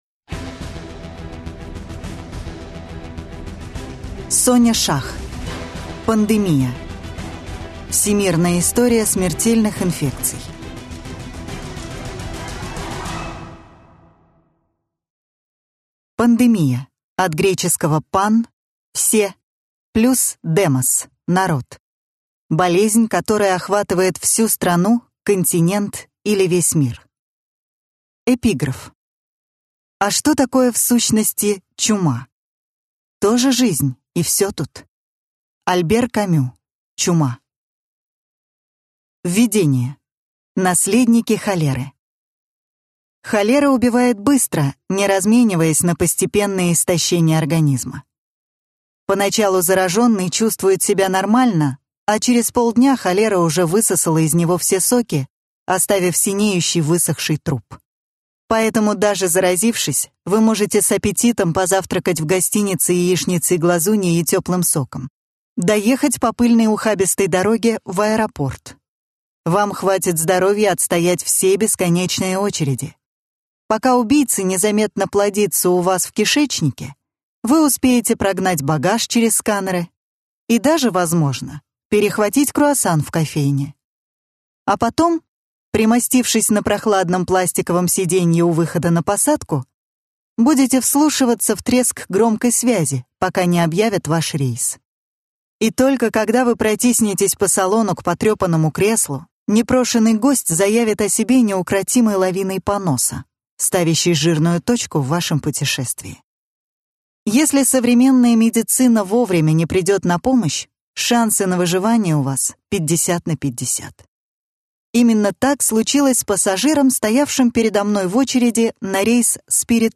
Аудиокнига Пандемия: Всемирная история смертельных вирусов | Библиотека аудиокниг